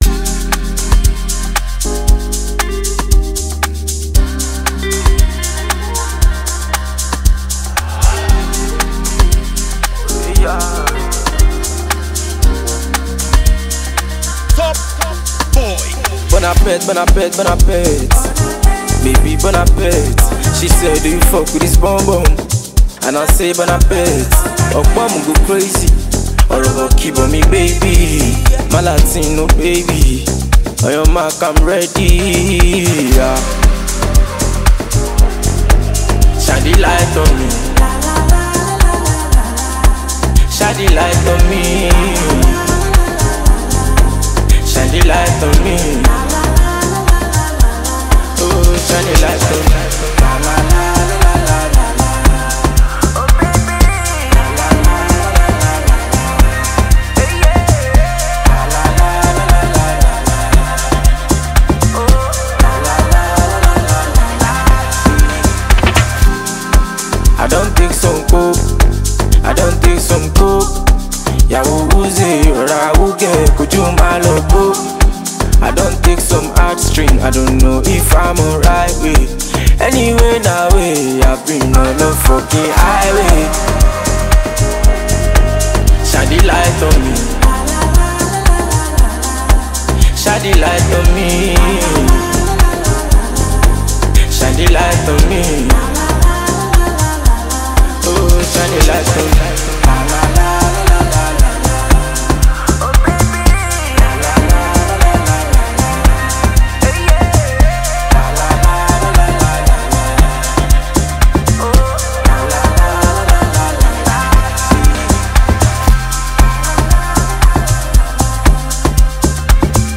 Nigerian superstar DJ and producer
fast-rising singer
blends infectious rhythm with streetwise melodies